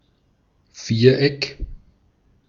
Ääntäminen
Synonyymit Glattbutt Ääntäminen Tuntematon aksentti: IPA: /ˈfiːʁˌɛk/ IPA: [ˈfiːɐ̯ˌʔɛkʰ] IPA: /ˈfiːr.ɛk/ Haettu sana löytyi näillä lähdekielillä: saksa Käännös Substantiivit 1. quadrilateral Artikkeli: das .